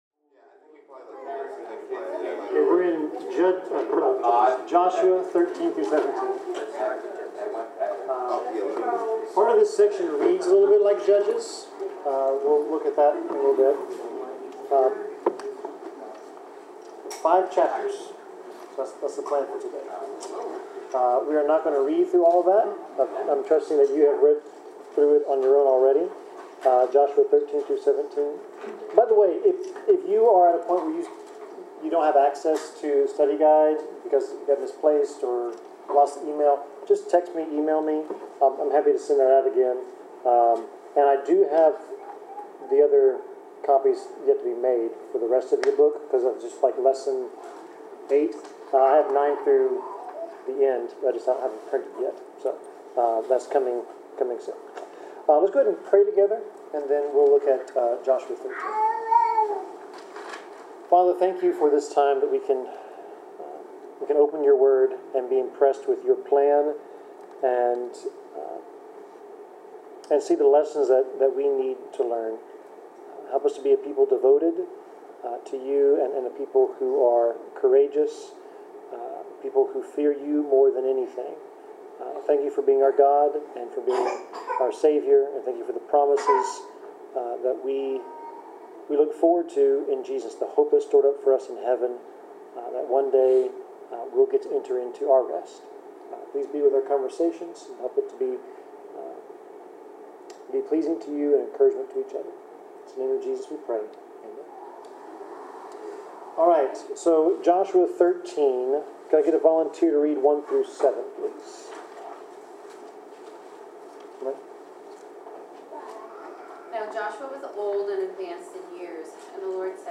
Due to unforeseen technical difficulties, the recording was cut short.
Bible class: Joshua 13-17